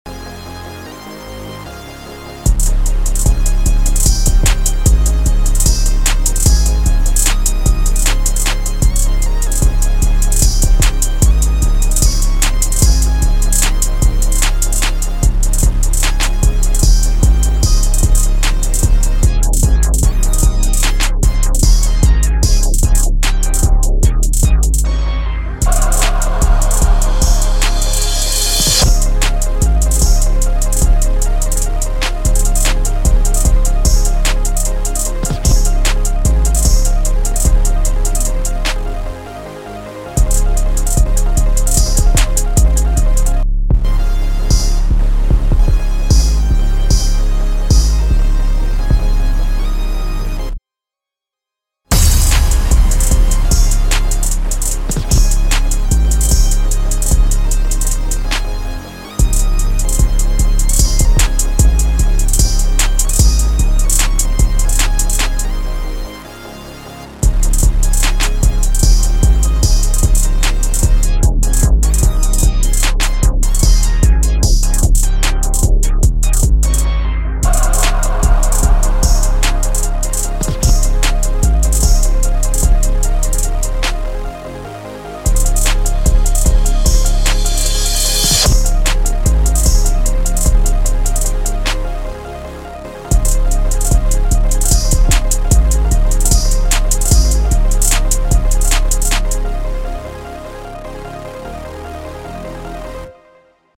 Жанр: hip-hop rap rage